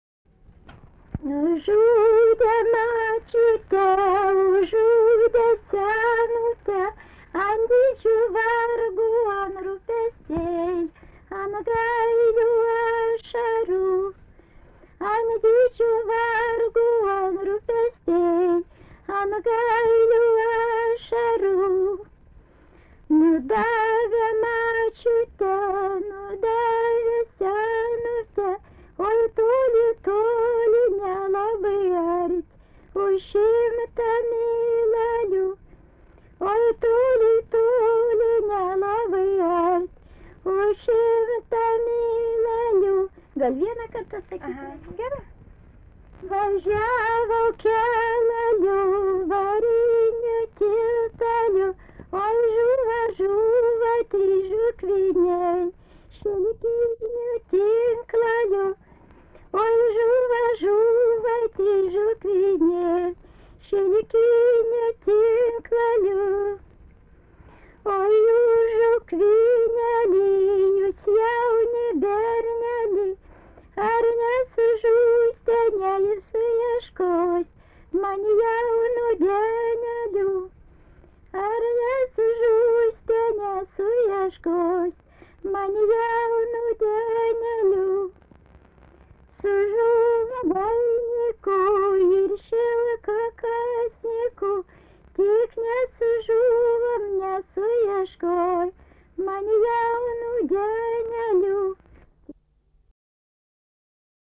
daina, vestuvių
Atlikimo pubūdis vokalinis